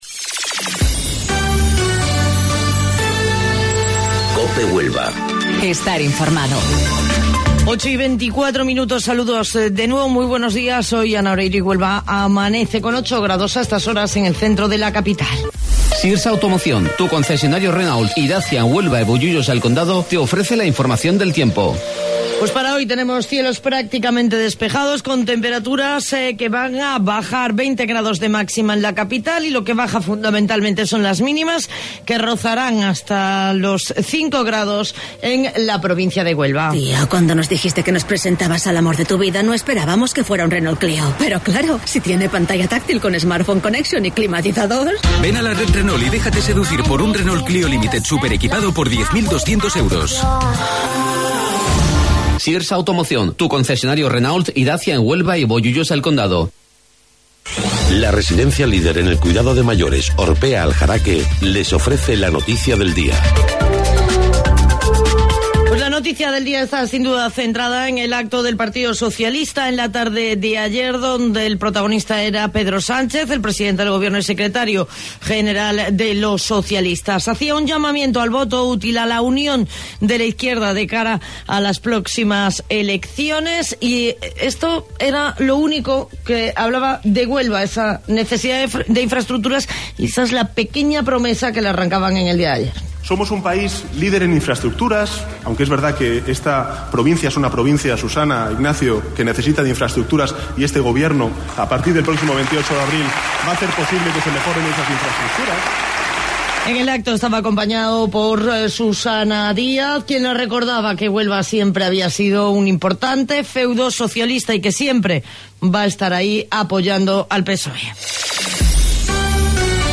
AUDIO: Informativo Local 08:25 del 4 de Abril